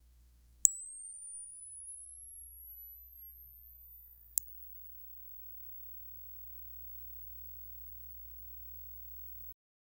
neon light flicker
neon-light-flicker-5cicfem3.wav